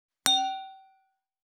320,コップ,食器,テーブル,チーン,カラン,キン,コーン,チリリン,カチン,チャリーン,クラン,カチャン,クリン,シャリン,チキン,コチン,カチコチ,チリチリ,シャキン,
コップワイン効果音厨房/台所/レストラン/kitchen室内食器